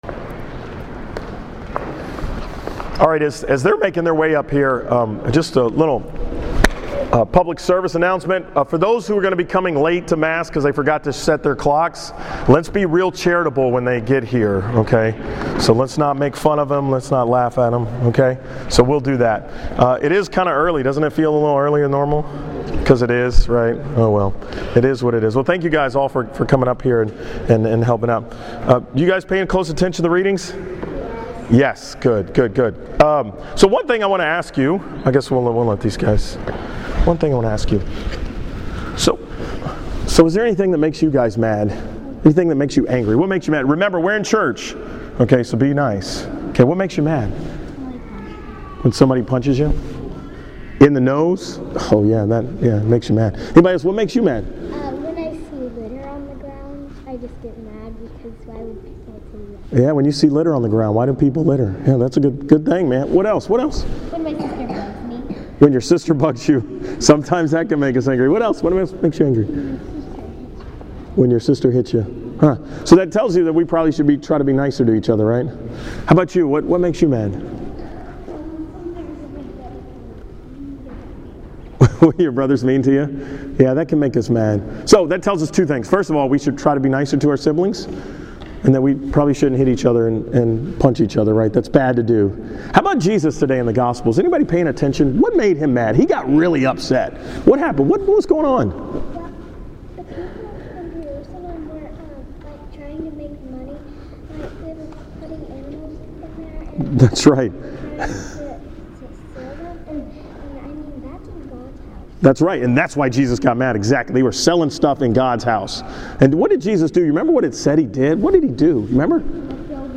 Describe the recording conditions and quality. From the 9 am Mass on Sunday, March 8, 2015